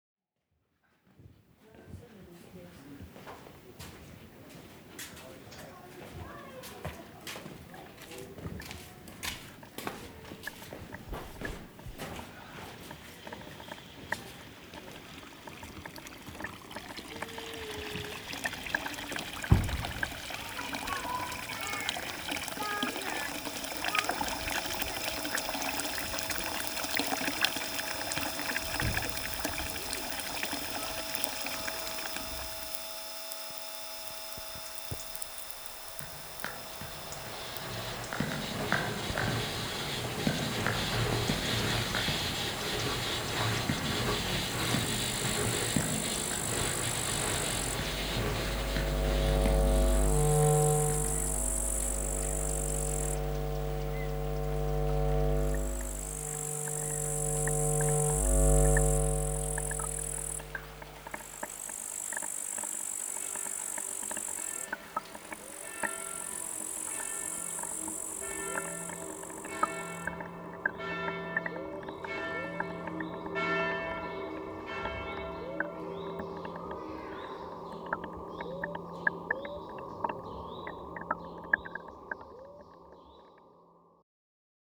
Die Teilnehmenden bauten Kontaktmikrofone, machten Feldaufnahmen und hörten anschließend gemeinsam die aufgenommenen Klänge von Karthaus an.
Through the experimental deployment of microphones, recording equipment and the acoustic architecture of the space, along with technical aids such as directional microphones, the workshop opened up new perspectives on the interplay of humans, sound and space. Participants built contact microphones, made field recordings, and then collectively listened to the captured sounds of Karthaus / Certosa.
Listen here the collective audio created during the workshop.